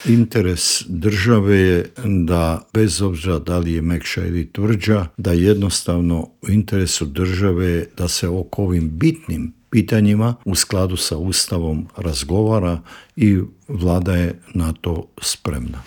O tome se proteklih dana razgovaralo i u Vladi, a posebni savjetnik premijera Andreja Plenkovića i bivši ministar vanjskih i europskih poslova Mate Granić u Intervjuu Media servisa rekao je da je Ured predsjednika informiran o svemu što radi Vlada RH.